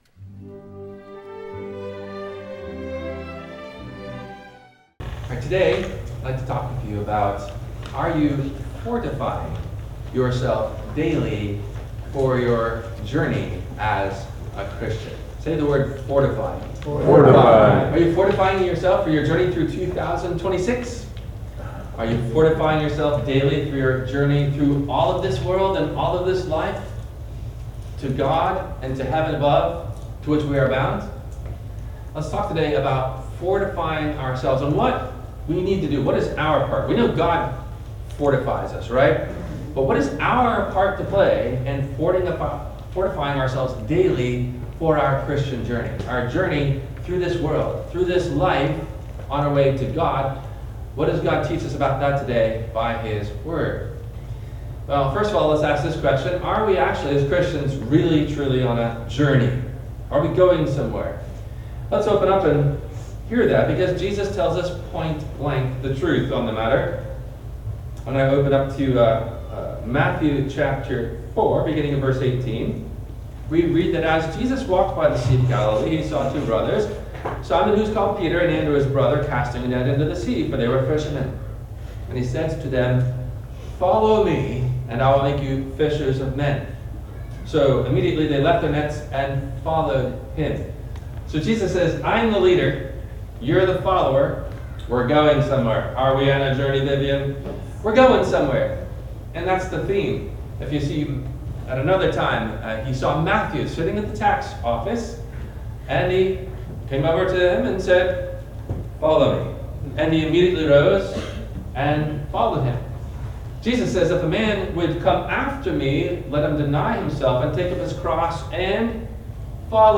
Are you FORTIFYING yourself for your journey through this world to God? – WMIE Radio Sermon – January 19 2026